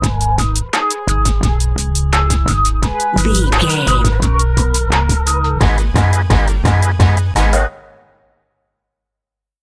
Aeolian/Minor
G#
drum machine
synthesiser
funky
aggressive
hard hitting